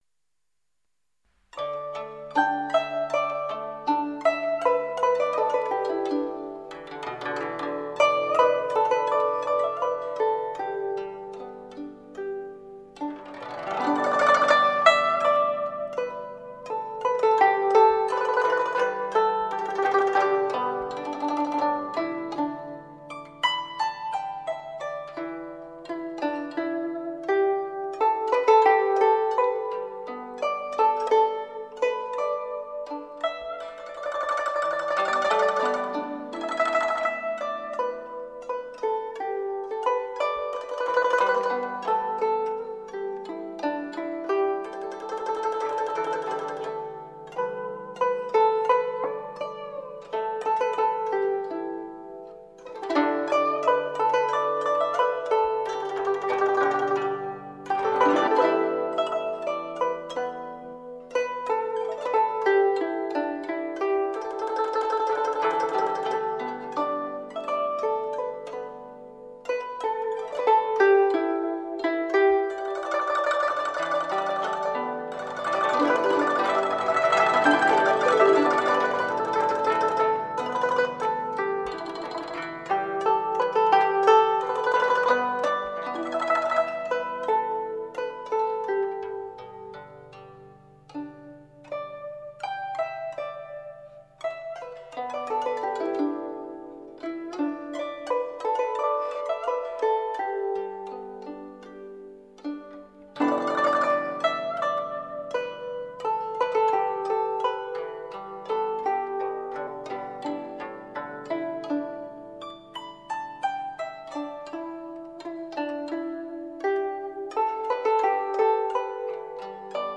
古筝演奏
熟悉的旋律，不一样的演绎，怀旧经典，纯粹如天籁古筝，唤起了我们久远的回忆。